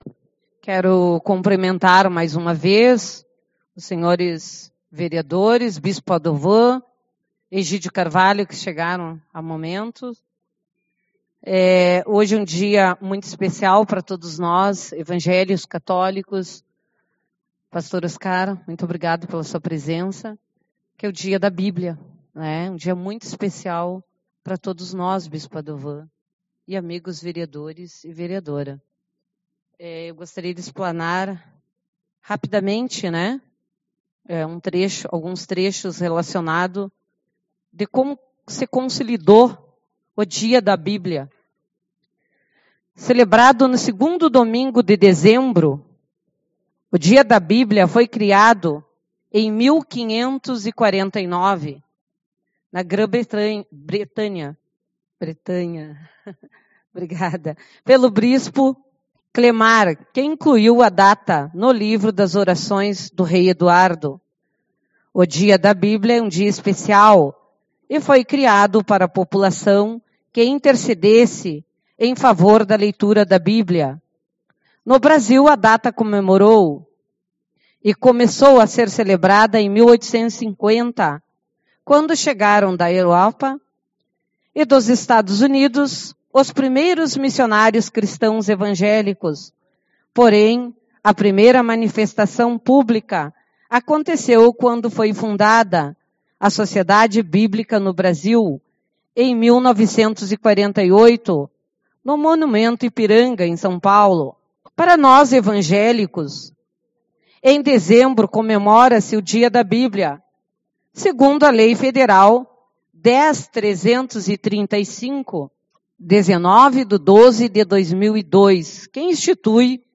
09/12 - Reunião Ordinária